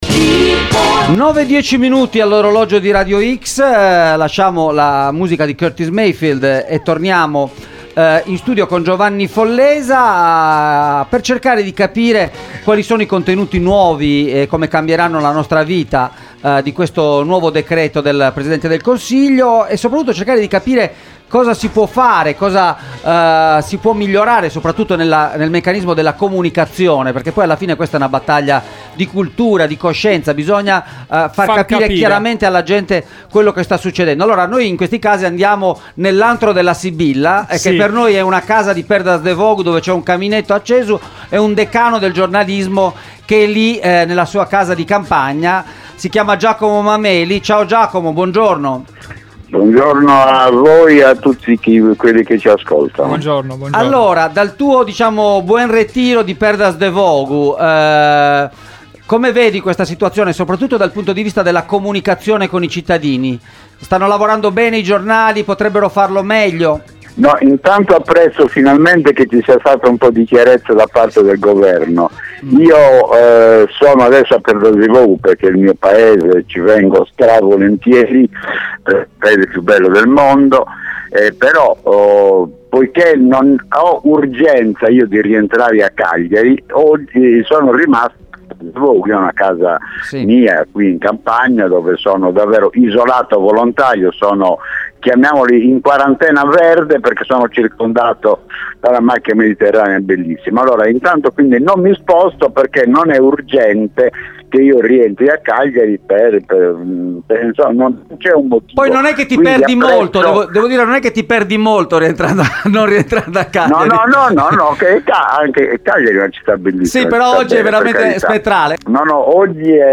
Coronavirus e informazione in Italia – intervista